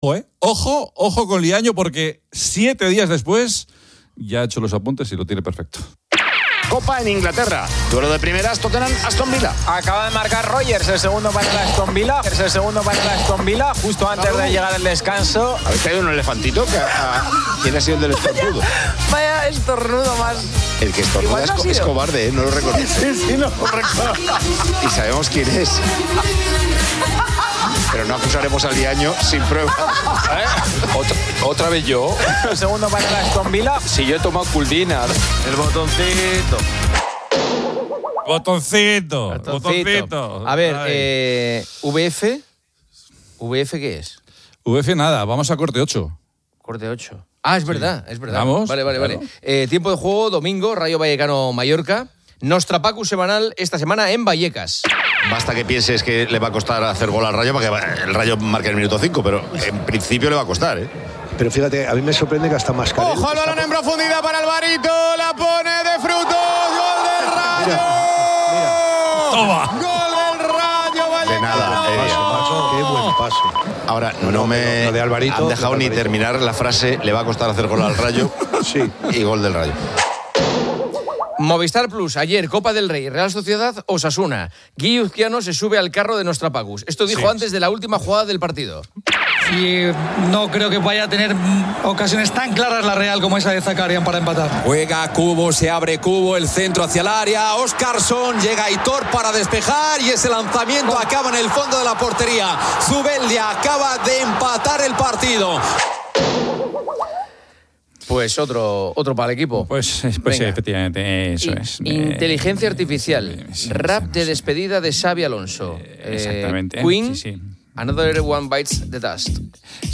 Se incluye un rap humorístico de Xavi Alonso despidiéndose del Real Madrid.